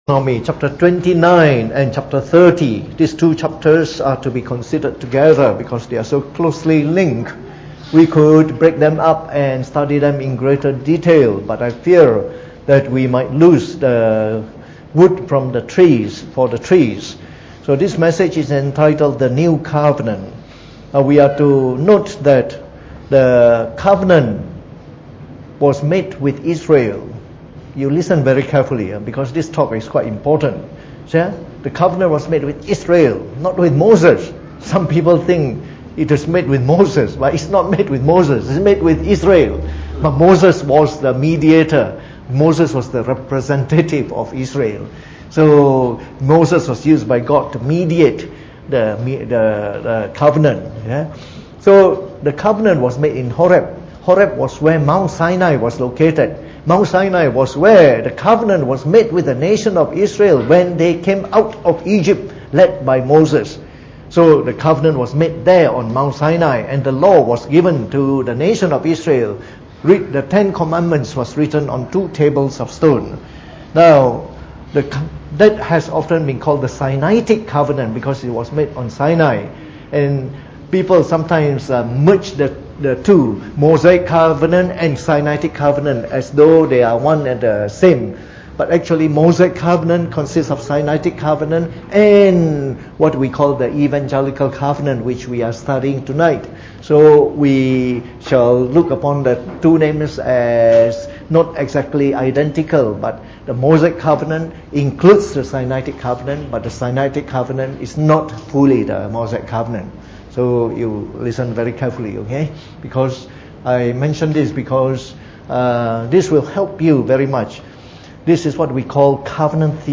Preached on the 3rd of October 2018 during the Bible Study, from our series on the book of Deuteronomy.